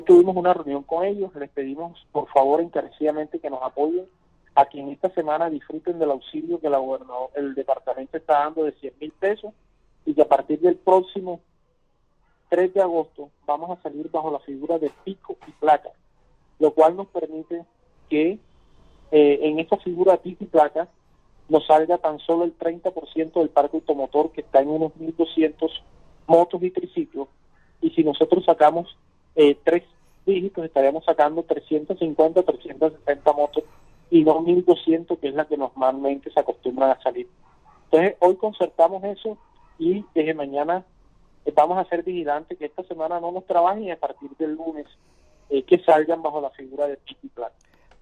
VOZ-ALCALDE-SABANAGRANDE-MOTOS.mp3